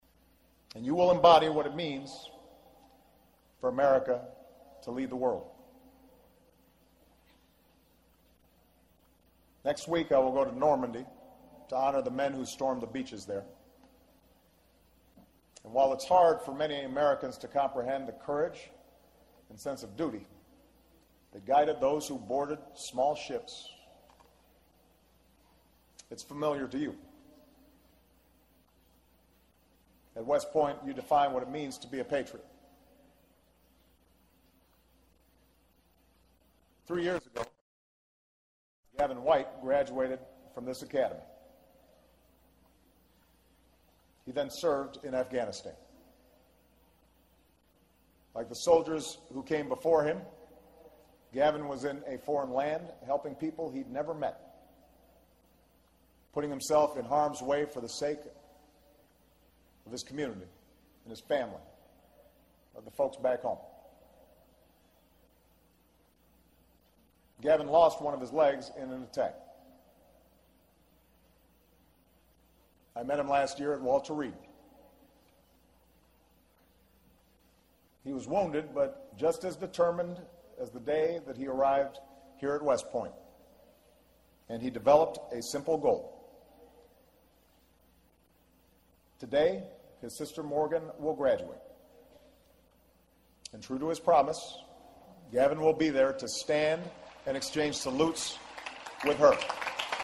公众人物毕业演讲 第119期:奥巴马美国军事学院(22) 听力文件下载—在线英语听力室